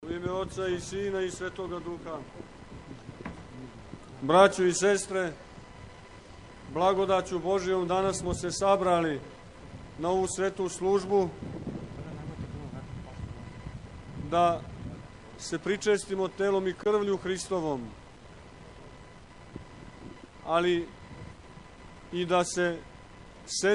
Овогодишњи литургијски спомен чуда које је Свети Архистратиг учинио у граду Хони (Колоси) одржано је у Сомбору, на Тргу Светог Георгија, код Светогеоргијевске цркве.
Беседа Епископа Порфирија: